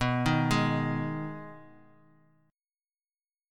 B6 Chord
Listen to B6 strummed